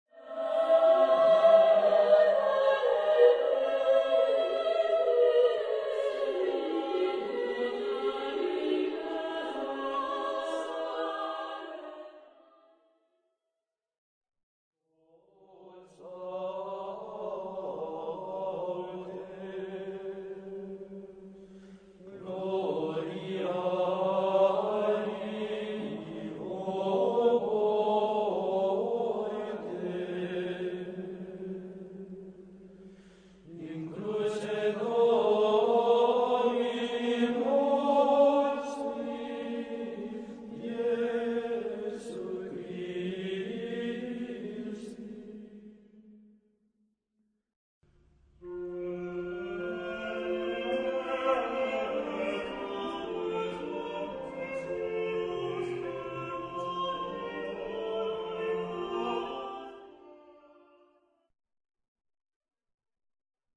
Ascolta il seguente brano: è composto da tre frammenti di cui uno solo è in stile gregoriano.